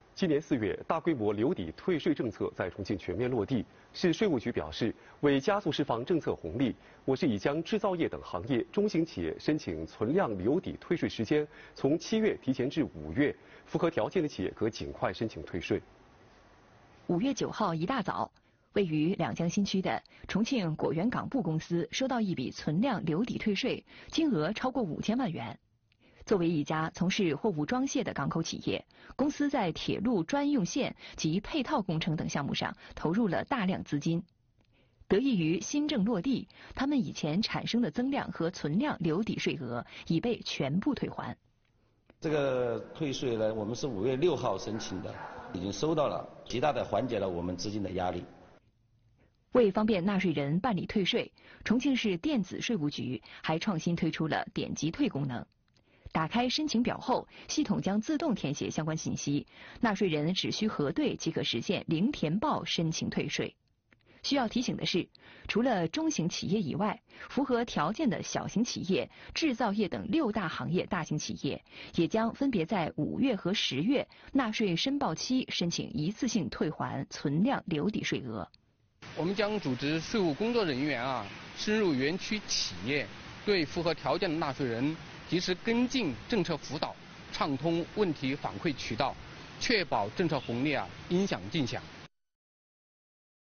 大规模留抵退税政策在重庆全面落地，5月起 ，中型企业存量留抵税额提前退！ 来源：重庆新闻联播 编辑：重庆税务